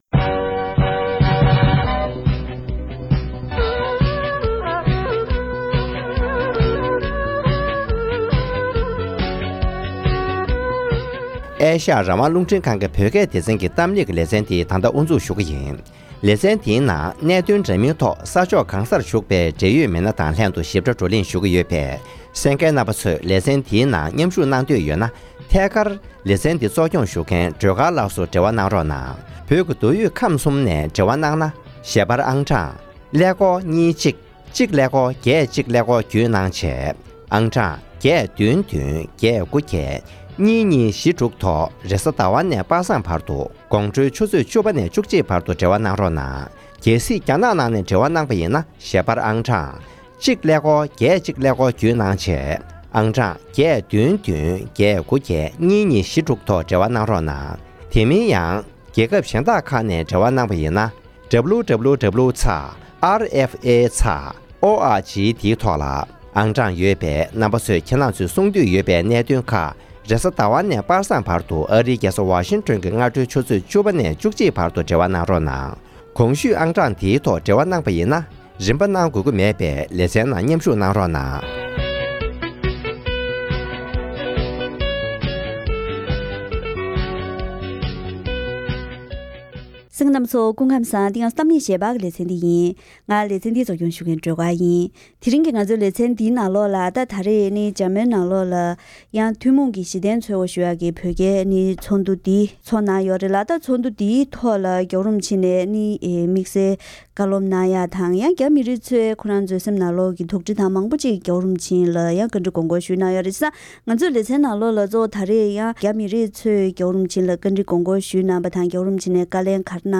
༄༅། །ཐེངས་འདིའི་གཏམ་གླེང་ཞལ་པར་ལེ་ཚན་འདིའི་ནང་། ཇཱར་མན་ལ་སྐོང་ཚོགས་གནང་བའི་བོད་རྒྱའི་ཚོགས་འདུའི་ཐོག་༸གོང་ས་མཆོག་ནས་ཚོགས་ཞུགས་ཀྱི་རྒྱ་མི་རིགས་ནས་བཀའ་འདྲི་ཞུས་པ་ཁག་ལ་བཀའ་ལན་གནང་བའི་ནང་དོན་ཁག་དང་། ཚོགས་འདུར་མཉམ་ཞུགས་གནང་མཁན་བོད་རྒྱའི་མི་སྣ་ཁག་ཅིག་དང་ལྷན་བཀའ་མོལ་ཞུས་པ་ཞིག་གསན་རོགས་གནང་།།